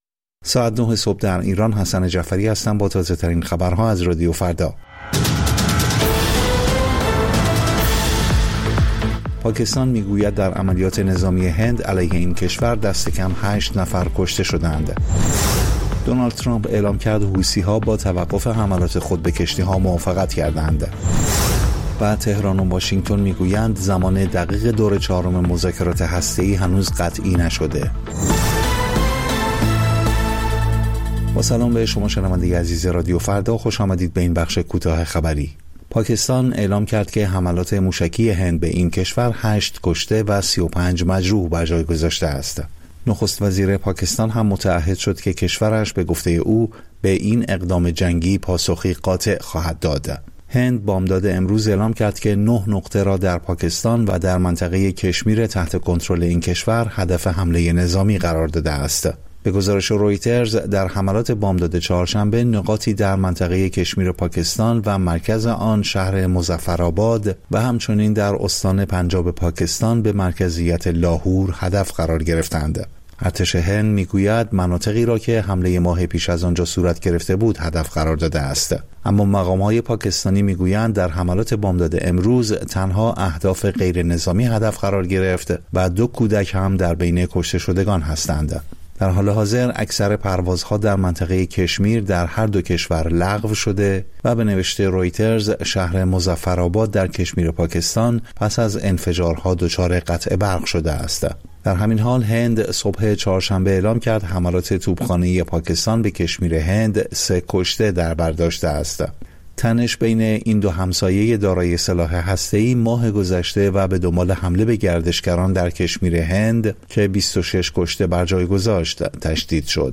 سرخط خبرها ۹:۰۰